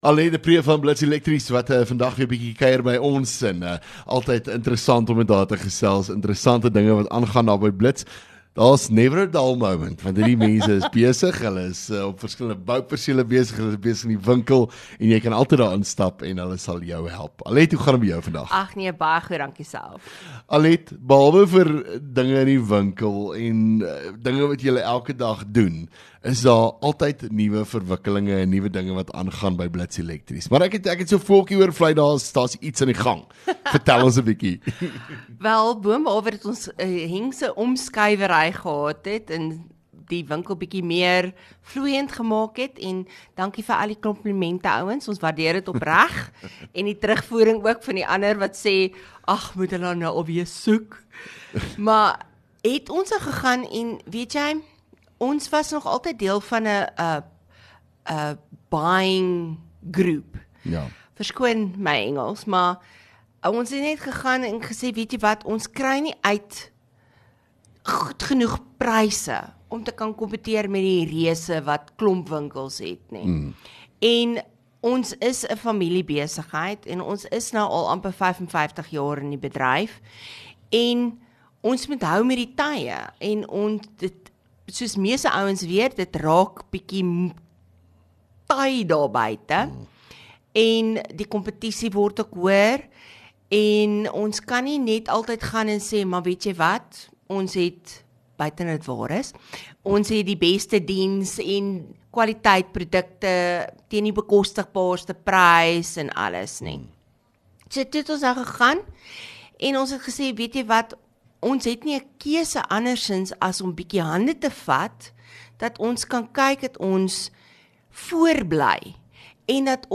LEKKER FM | Onderhoude 3 Mar Blits Elektrisiëns